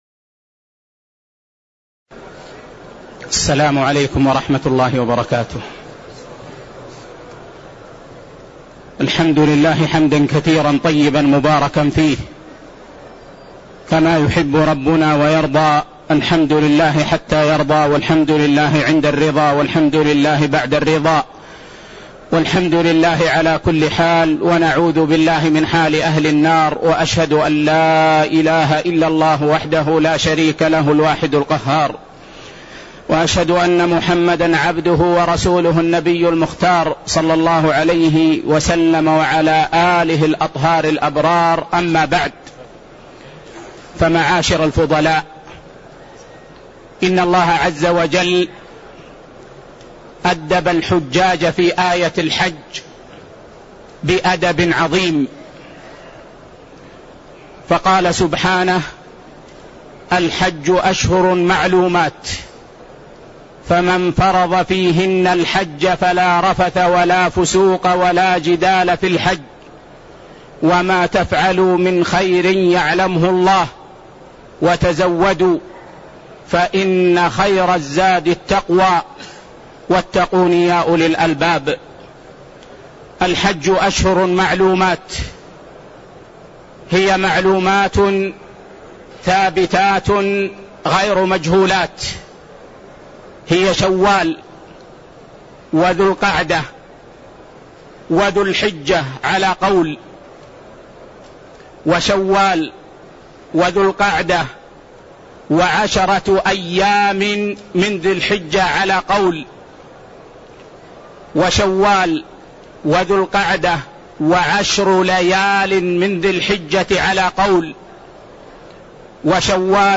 تاريخ النشر ١ ذو الحجة ١٤٣٣ هـ المكان: المسجد النبوي الشيخ